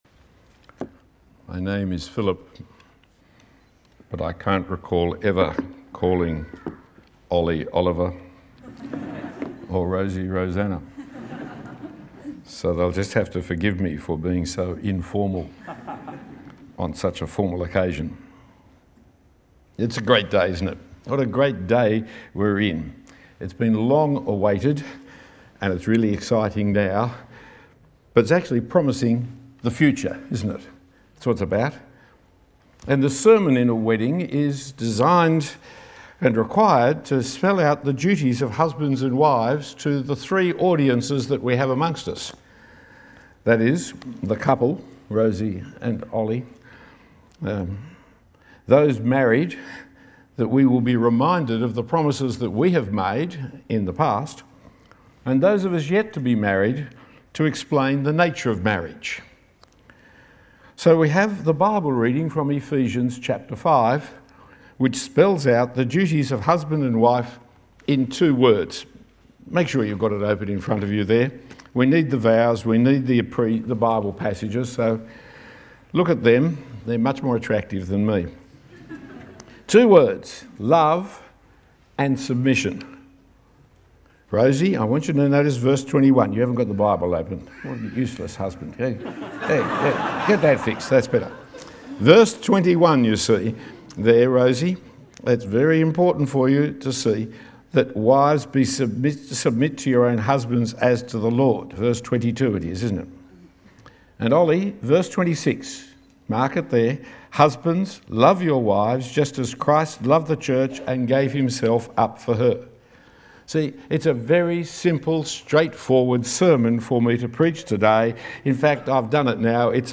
The sermon given at the wedding